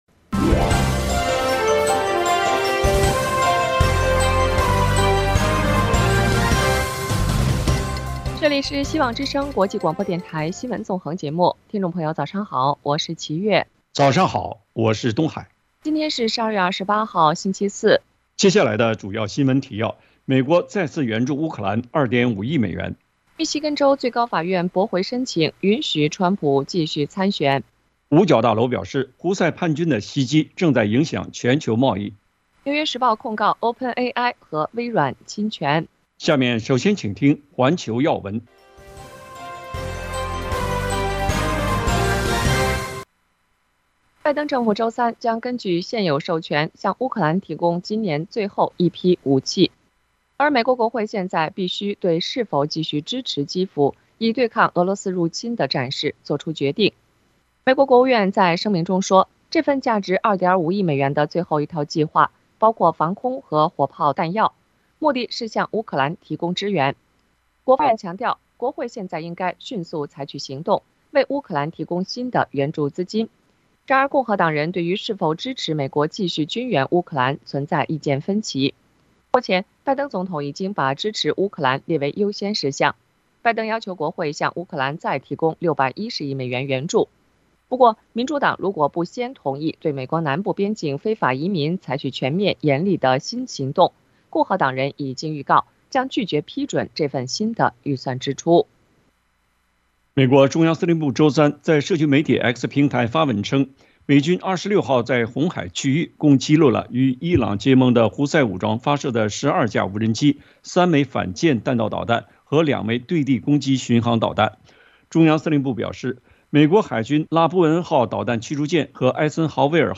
共和党知名女性将登台助选 川普副总统人选已出现端倪【晨间新闻】